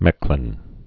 (mĕklĭn)